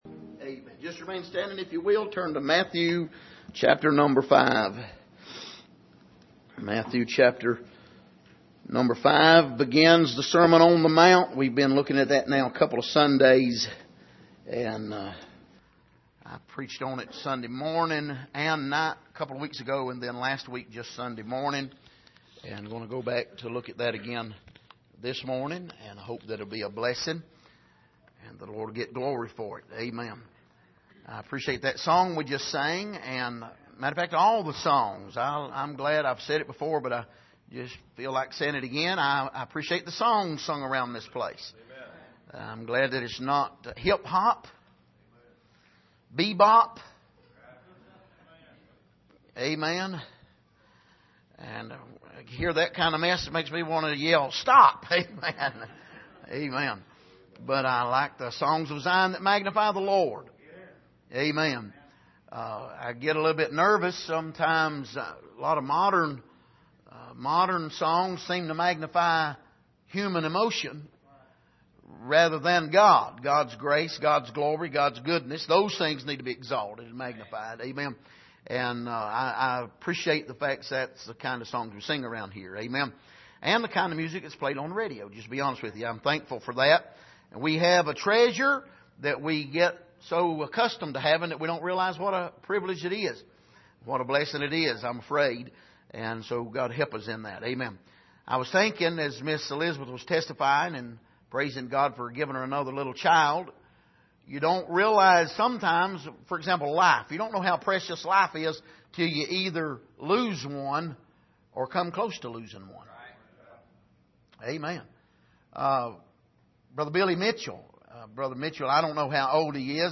Passage: Matthew 5:1-12 Service: Sunday Morning